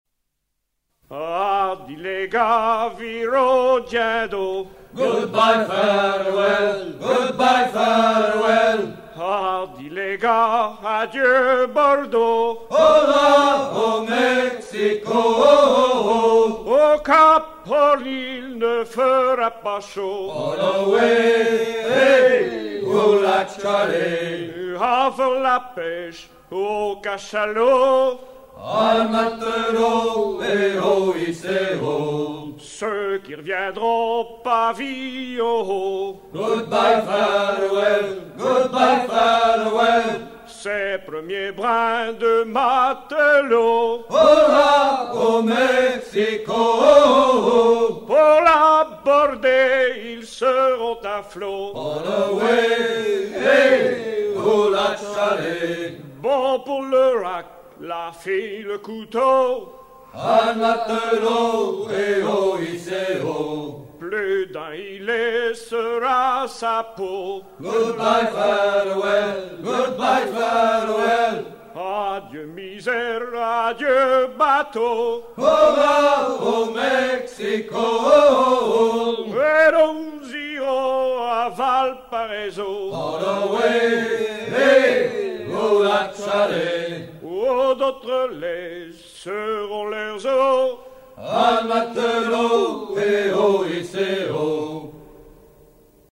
Hardi les gars vire au guindeau Détails de l'archive Titre Hardi les gars vire au guindeau Origine du titre : Editeur Note chanson spécifique du répertoire de bord. Chant de baleiniers
Pièce musicale éditée